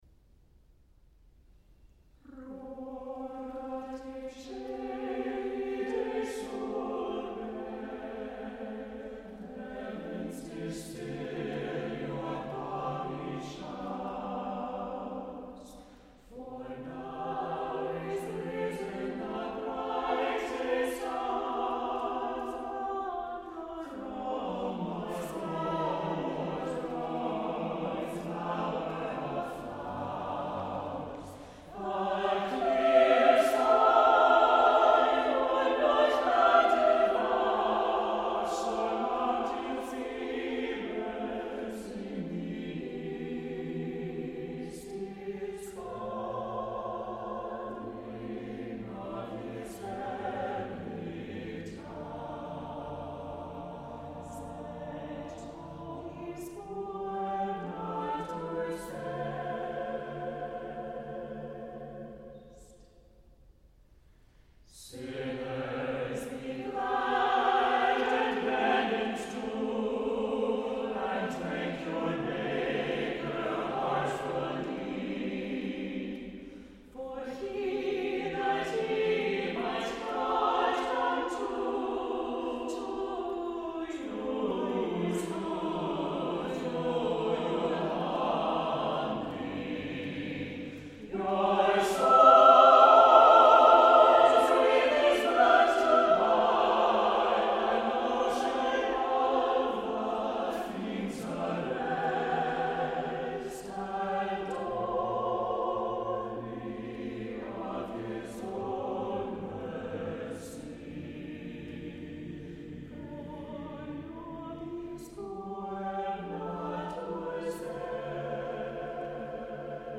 An extended Christmas anthem
the text is highlighted by changes of meter and key